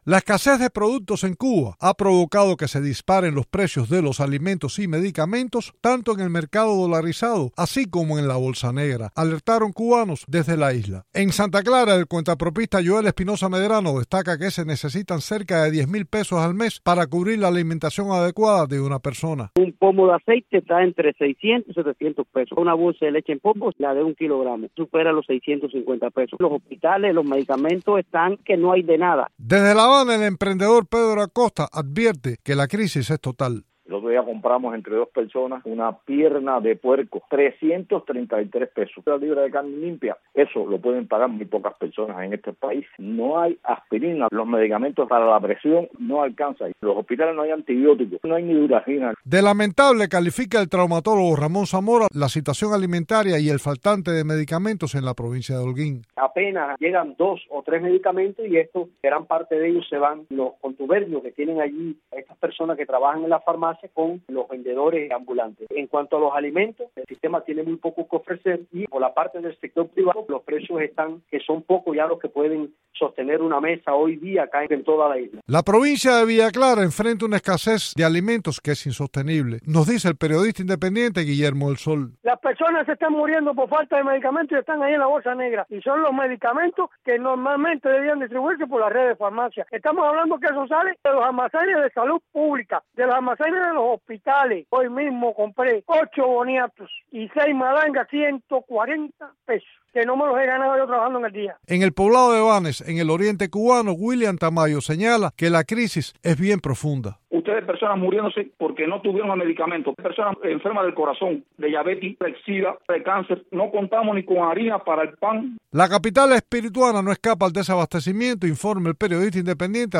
habló sobre el tema con cubanos de la isla